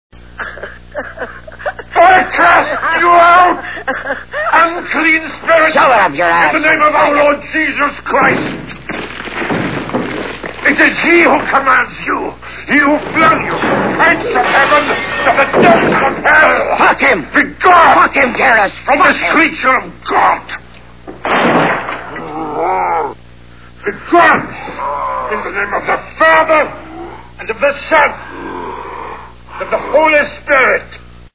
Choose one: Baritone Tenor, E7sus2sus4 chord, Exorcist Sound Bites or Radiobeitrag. Exorcist Sound Bites